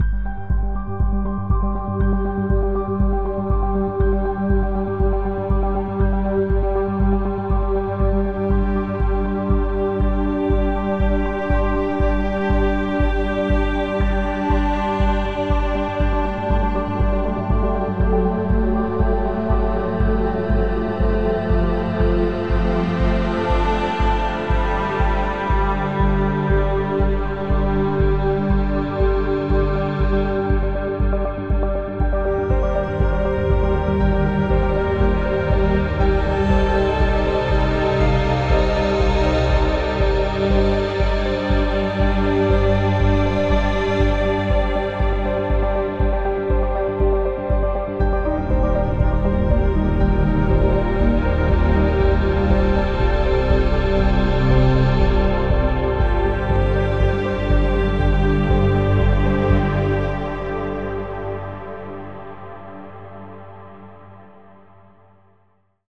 Added Ambient music pack. 2024-04-14 17:36:33 -04:00 18 MiB Raw Permalink History
Ambient Exploration cut 60.wav